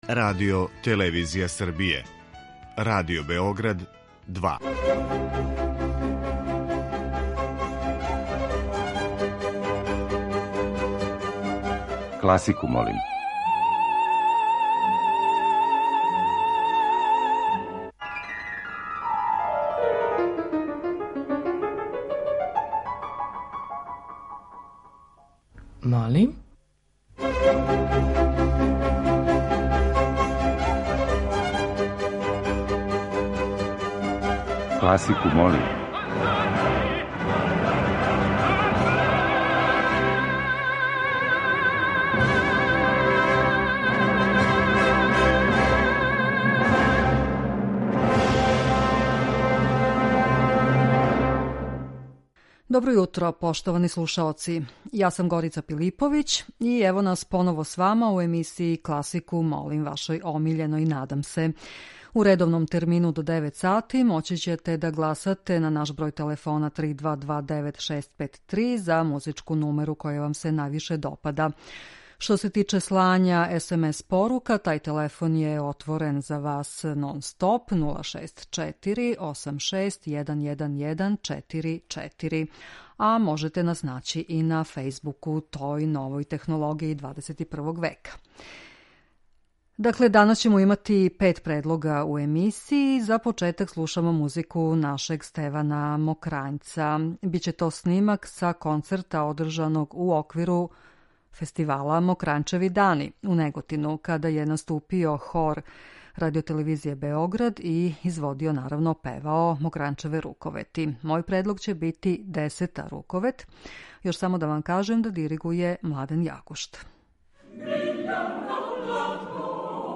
Избор за топ-листу класичне музике Радио Београда 2
klasika.mp3